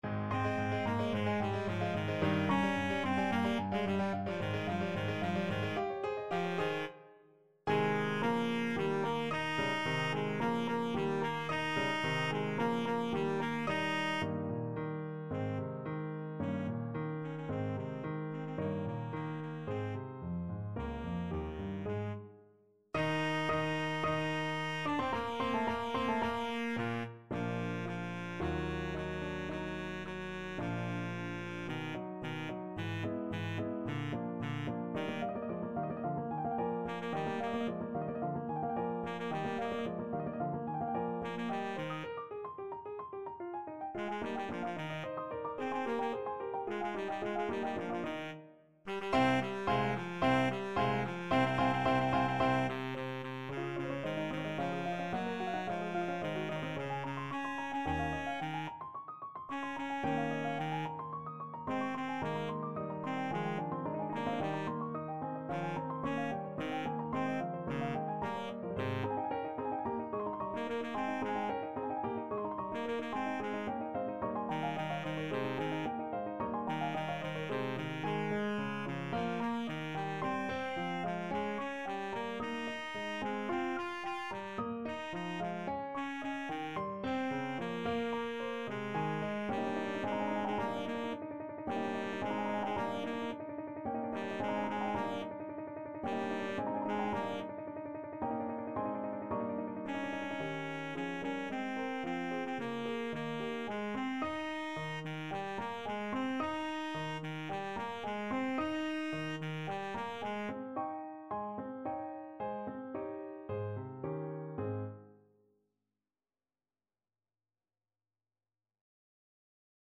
4/4 (View more 4/4 Music)
Allegro assai =220 (View more music marked Allegro)
Classical (View more Classical Baritone Saxophone Music)